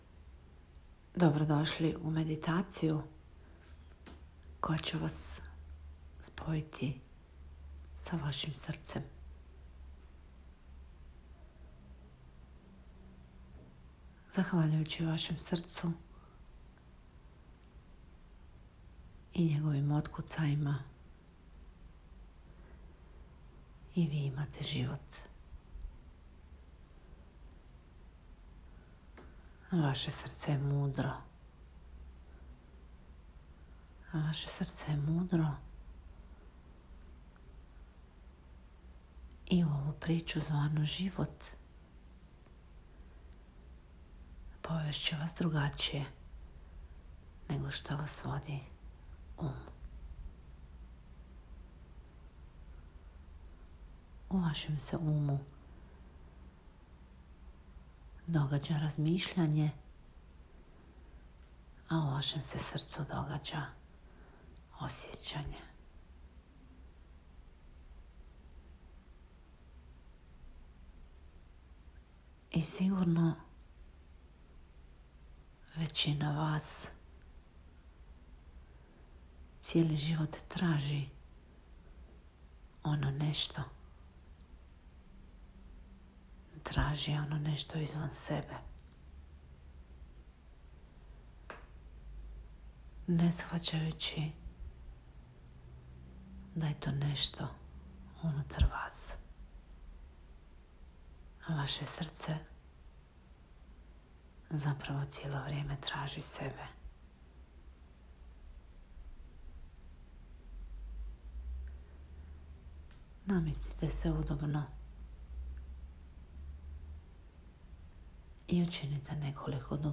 Meditacija-srca_DB_free.m4a